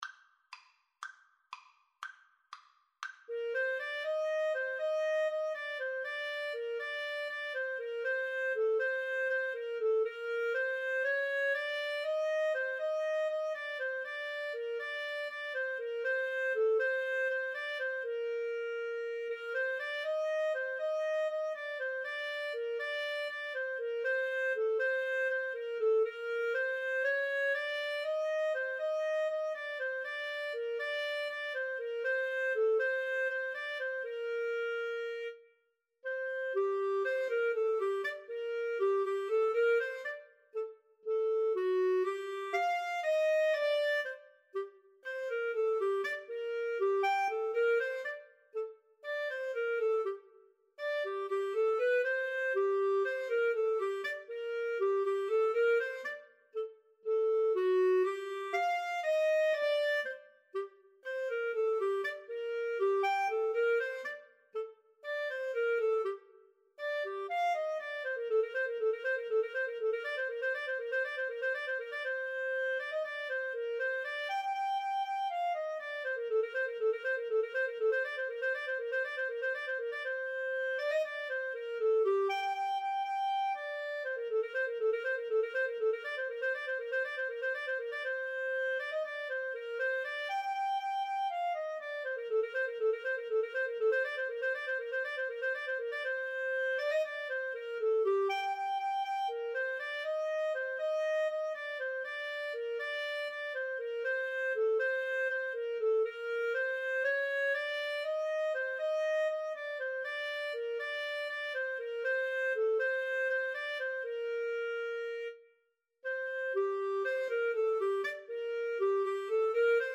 Free Sheet music for Clarinet Duet
G minor (Sounding Pitch) A minor (Clarinet in Bb) (View more G minor Music for Clarinet Duet )
Fast Two in a Bar =c.120
Traditional (View more Traditional Clarinet Duet Music)